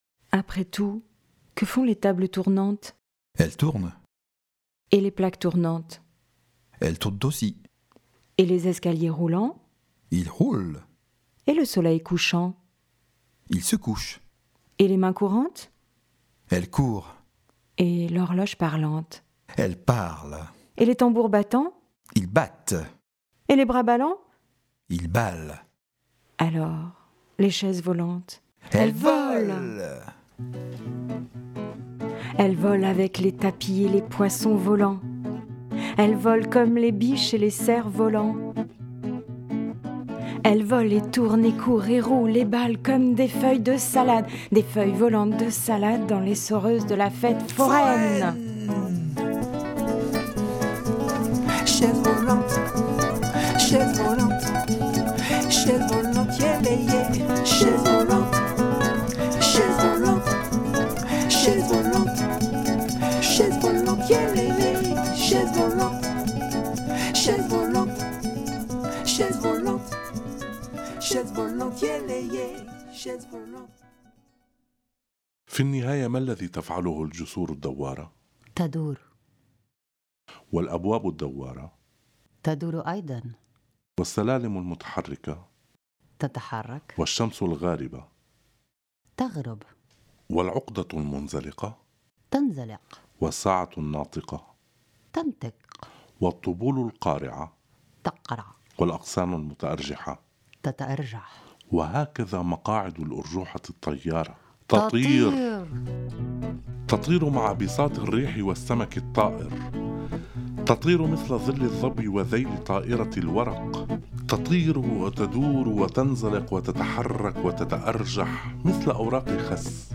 création musicale
lecture en français
lecture en arabe
09-Chaises-volantes-francais-et-arabe.mp3